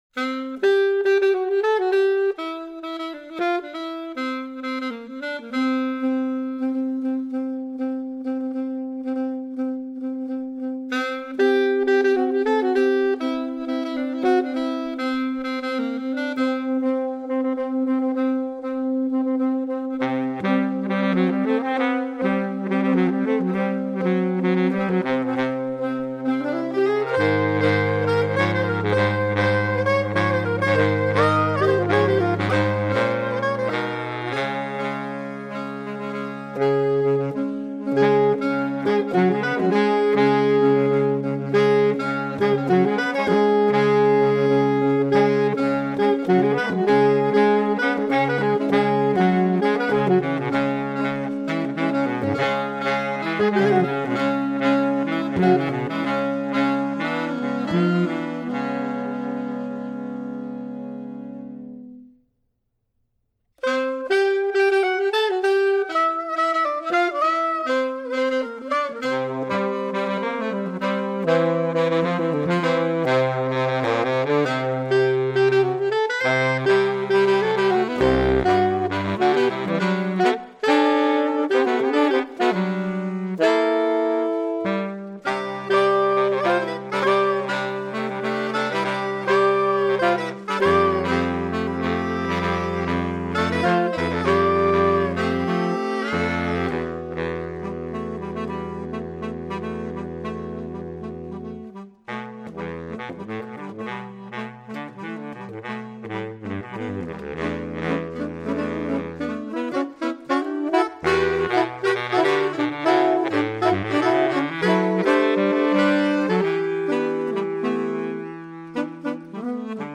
Saxophone Quartet (SATB)